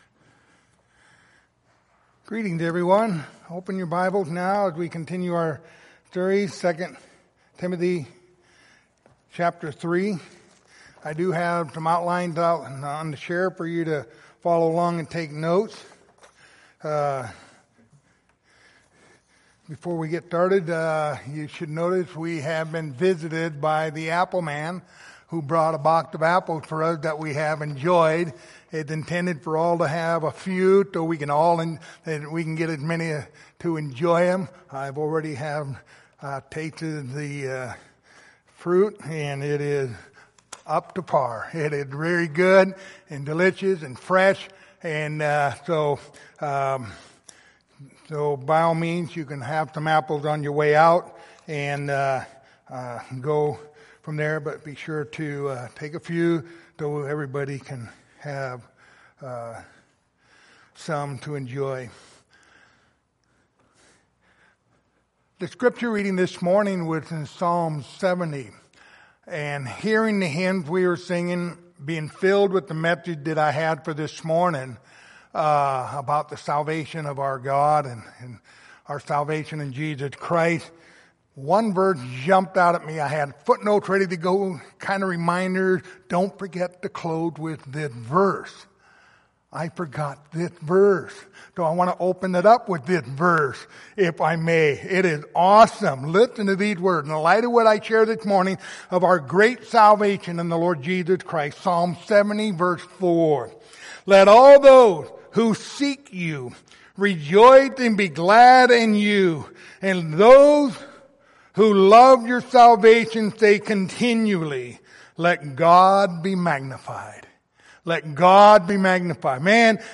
Passage: 2 Timothy 3:16-17 Service Type: Sunday Evening